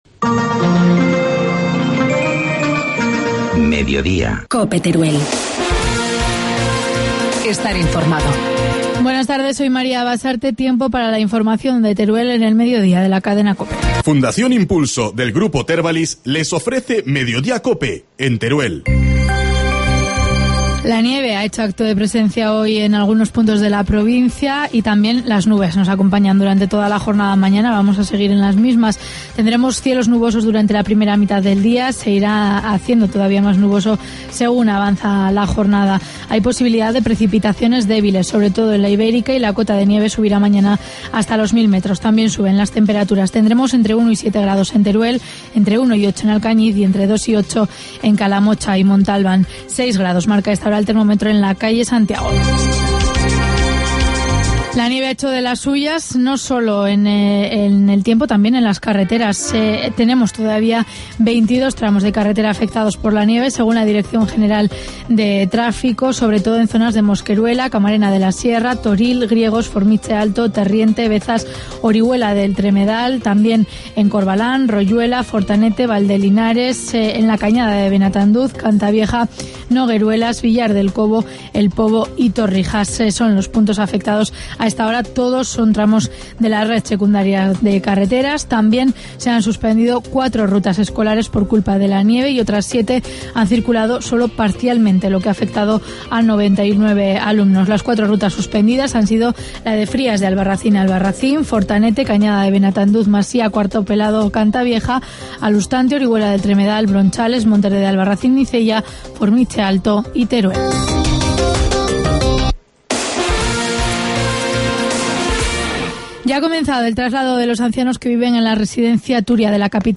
Informativo mediodía, miércoles 23 de enero